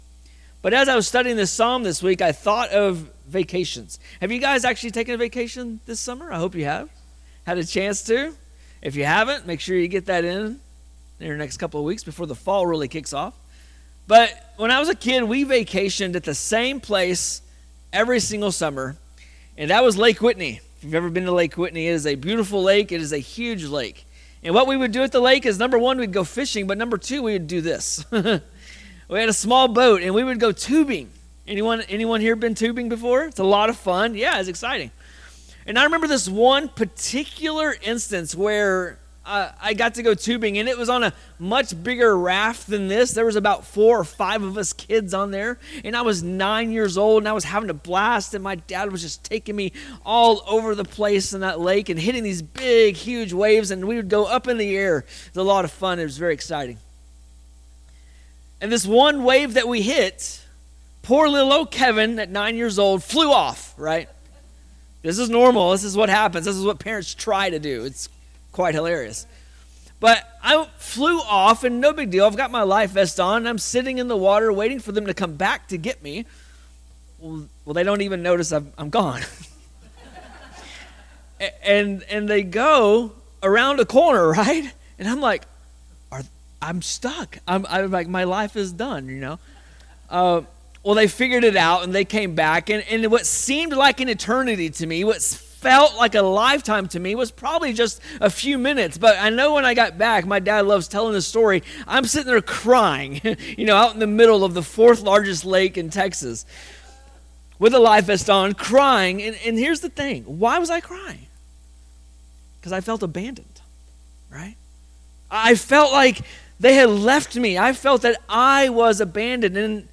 Sermons | Gainesville Bible Church